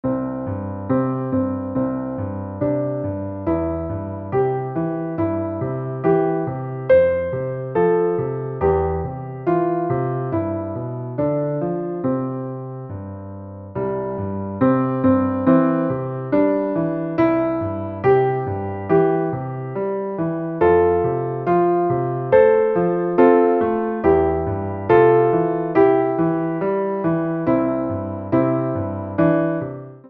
ピアノ伴奏